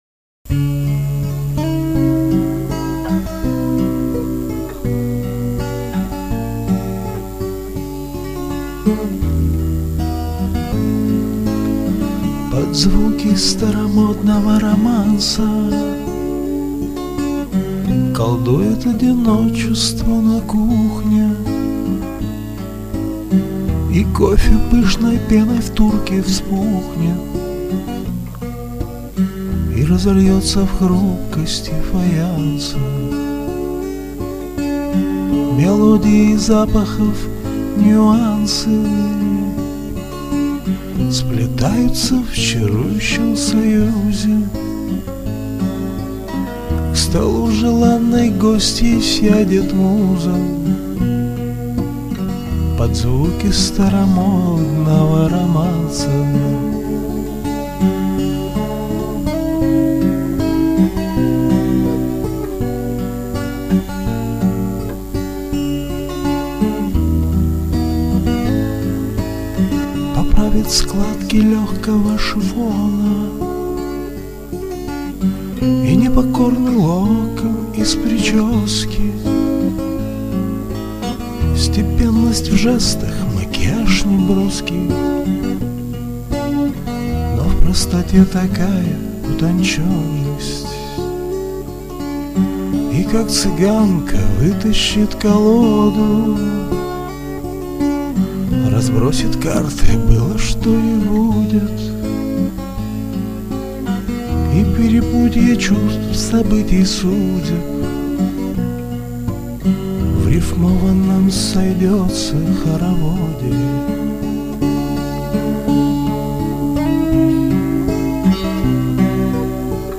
Музыкальное творчество